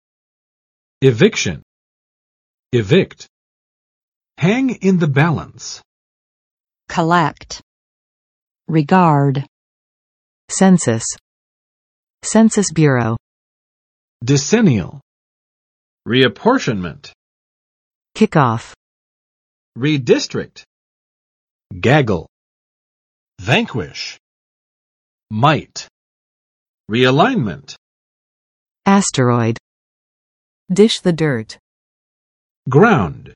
[ɪˋvɪkʃən] n. 逐出, 趕出